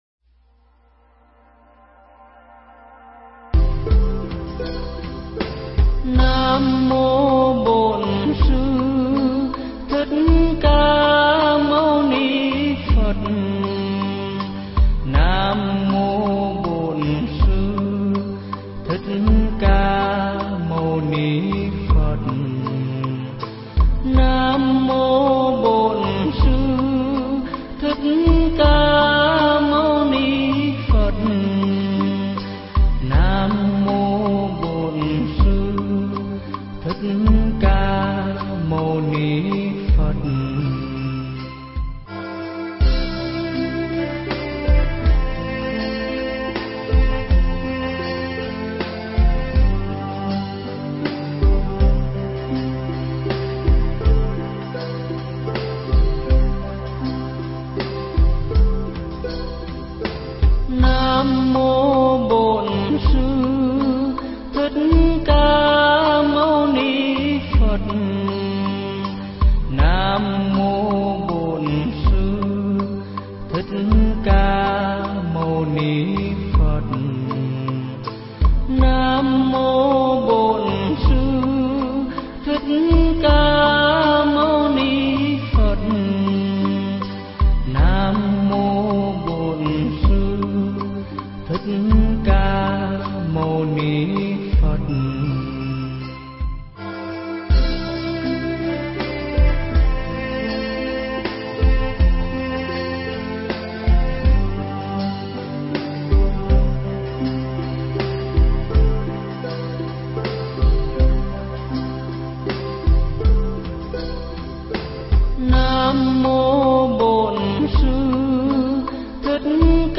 Nghe Mp3 thuyết pháp Phật Giáo thời đức Phật 2